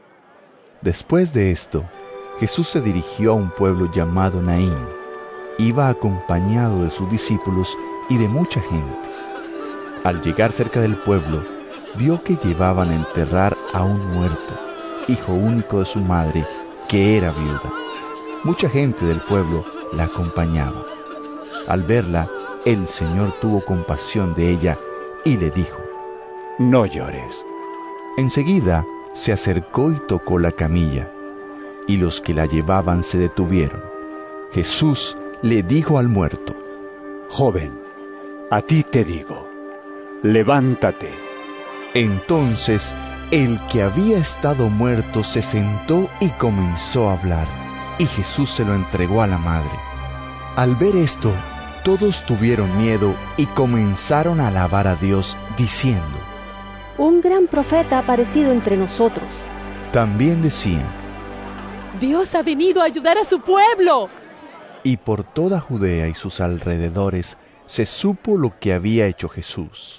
Lc 7 11-17 EVANGELIO EN AUDIO